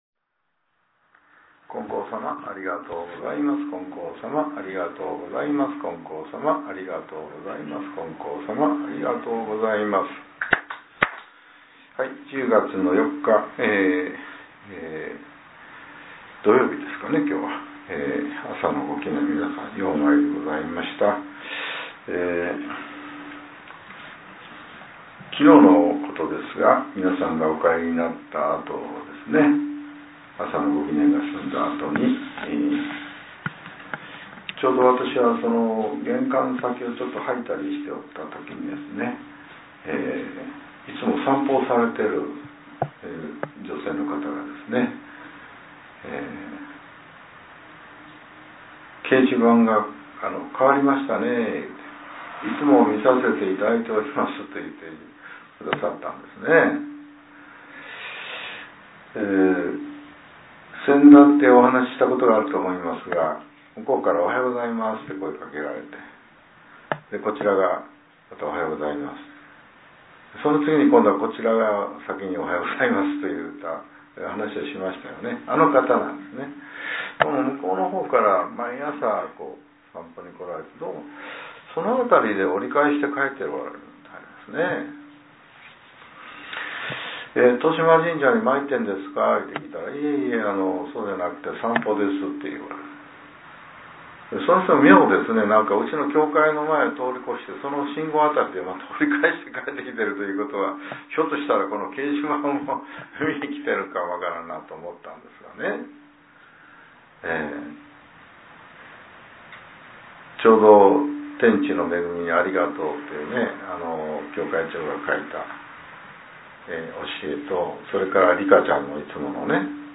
令和７年１０月４日（朝）のお話が、音声ブログとして更新させれています。